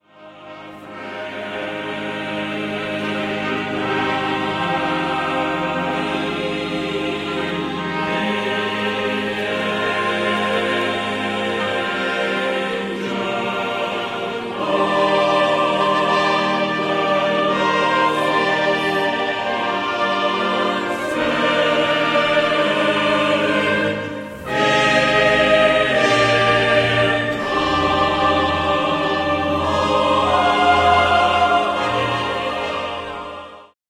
for SATB Choir, Orchestra and Synthesizer